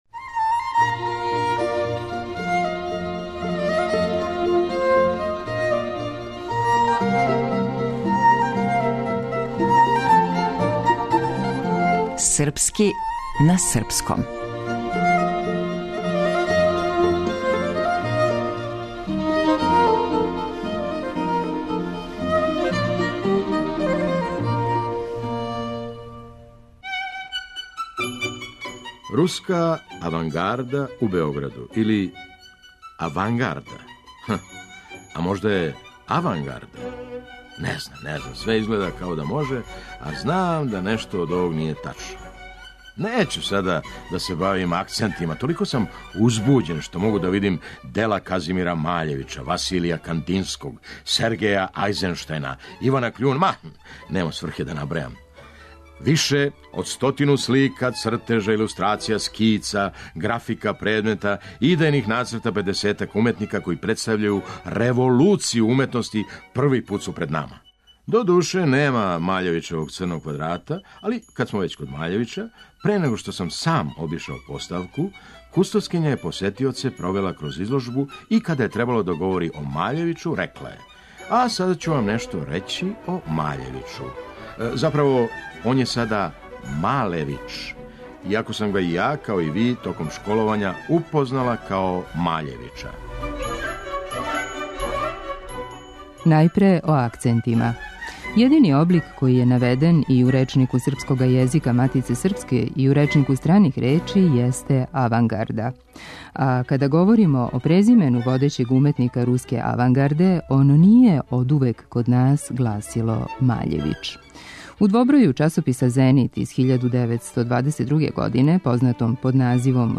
Драмски уметник - Феђа Стојановић